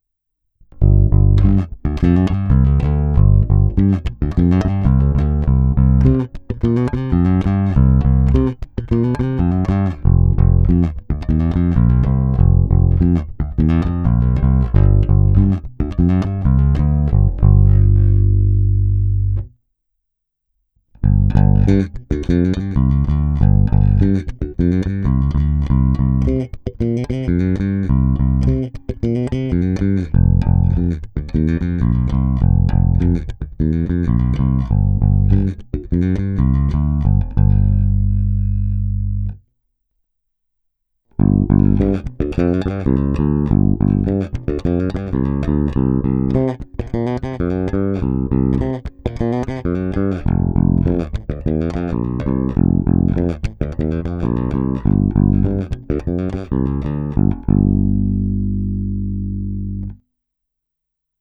Není-li uvedeno jinak, následující nahrávky jsou provedeny rovnou do zvukové karty, jen normalizovány, jinak ponechány bez úprav.
Není-li uvedeno jinak, hráno bylo v pasívním režimu s plně otevřenou tónovou clonou.
Snímač u kobylky